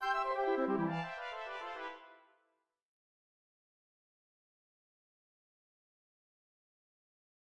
Mais un jazz délirant et démoniaque qui va donner la part belle au dialogue en forme de lutte entre le soliste et l’orchestre.
Tout commence donc par cette chute brutale au chiffre 14 qui nous mène dans une tonalité de mi majeur, référence au début de l’oeuvre qui se situait sur pédale de mi.